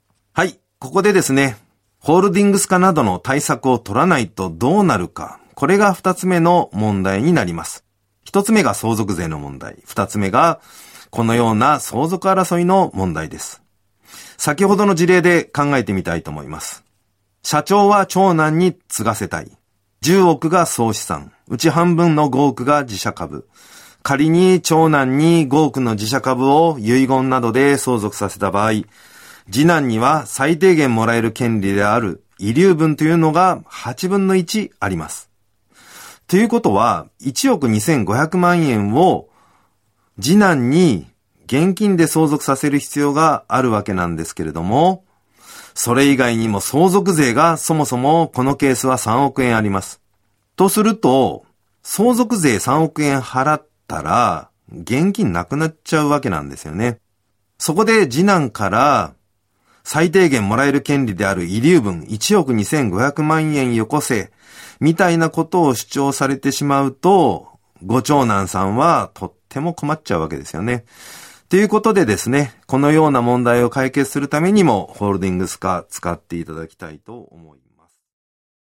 「賢いホールディングス化戦略」講話のサンプル音声をお聴きいただけます。